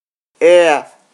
20 KB Trây /eə/ 1